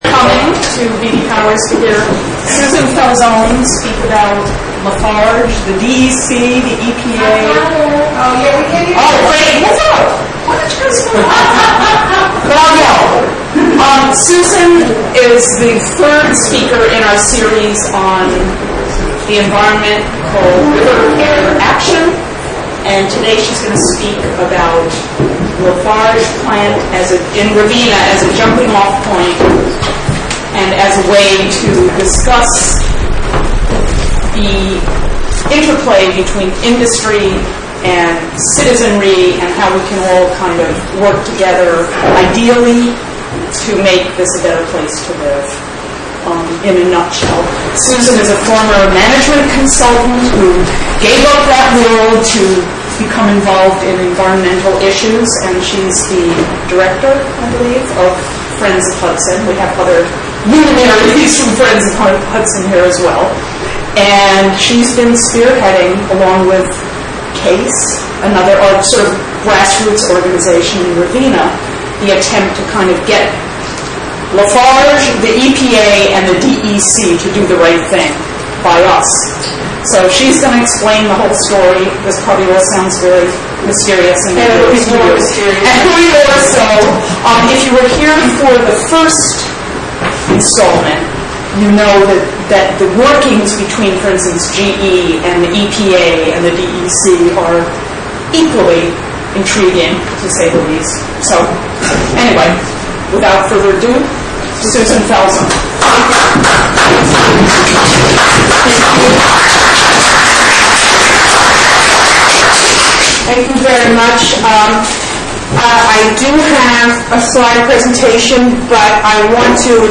live from Beattie-Powers Place in Catskill.